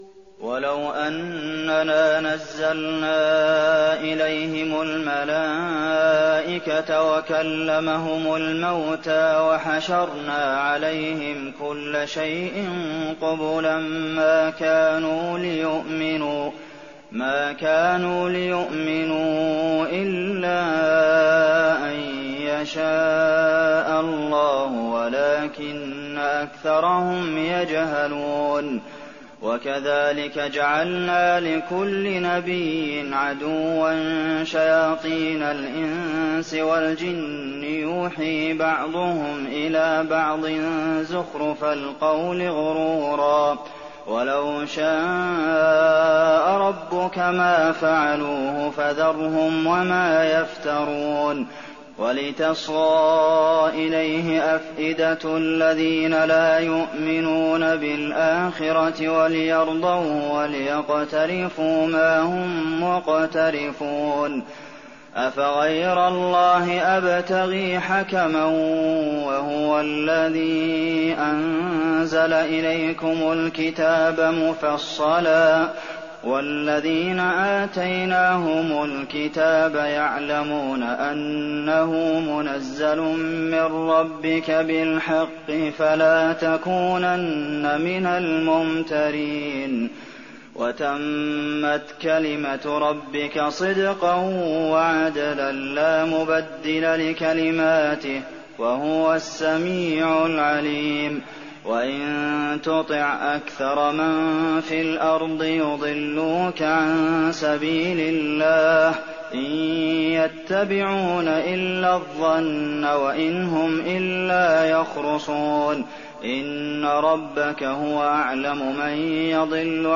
تراويح الليلة الثامنة رمضان 1419هـ من سورة الأنعام (111-165) Taraweeh 8th night Ramadan 1419 H from Surah Al-An’aam > تراويح الحرم النبوي عام 1419 🕌 > التراويح - تلاوات الحرمين